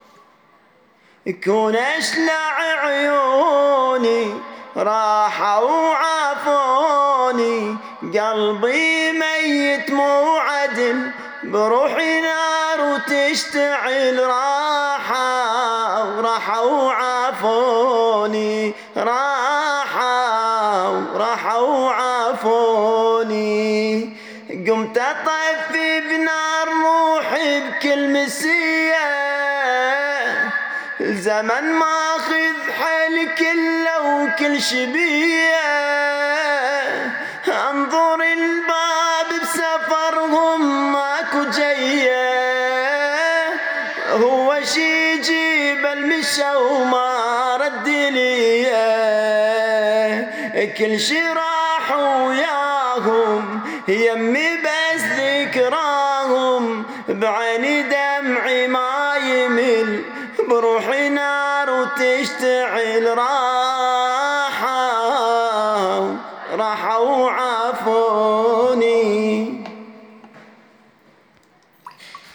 وهذا لحن القصيدة/ قصائد حسينية مكتوبة مع اللحن